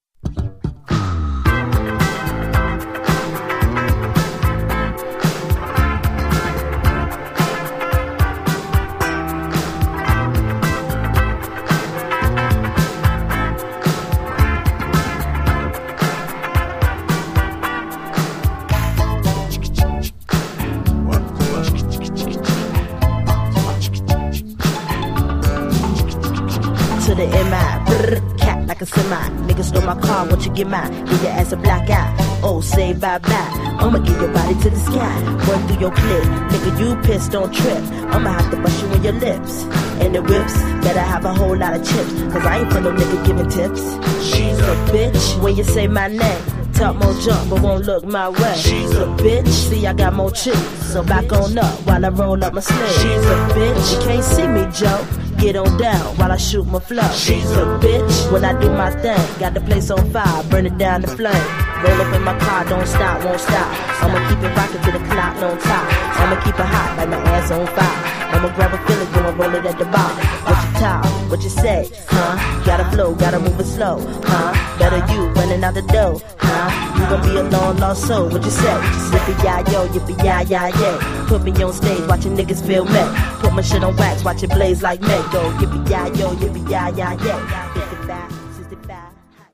111 bpm